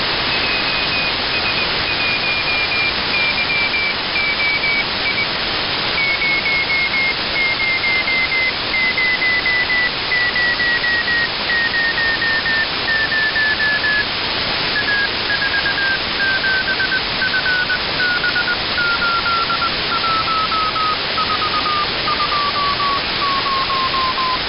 received the CW signal of CosmoGirl-Sat